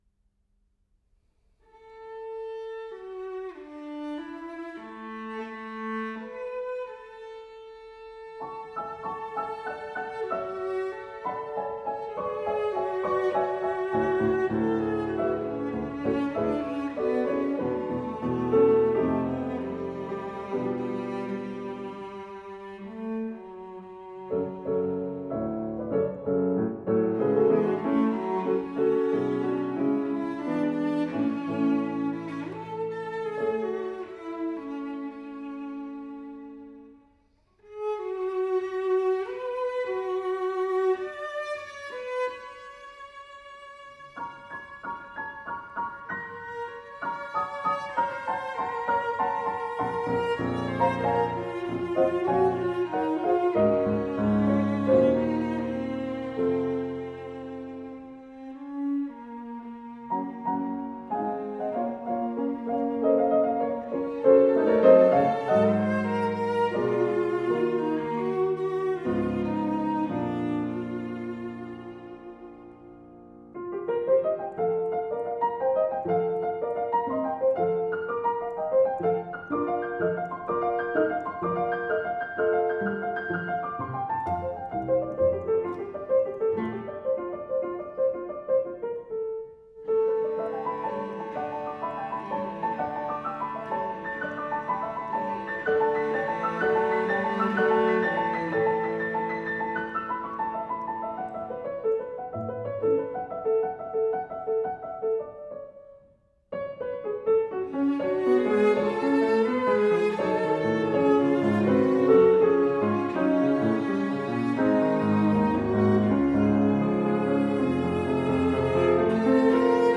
For Cello and Piano